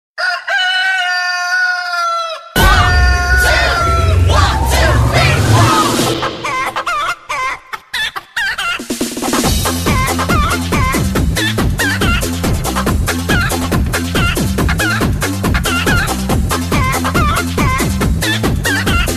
Chicken "Funny" Ringtone
funny_chicken.mp3